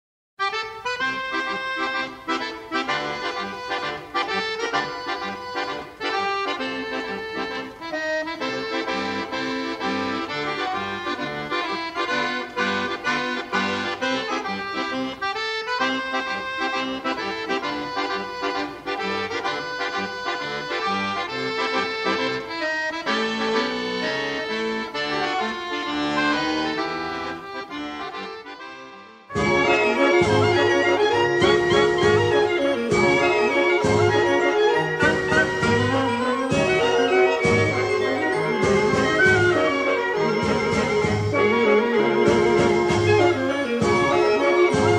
in the best-possible monaural sound.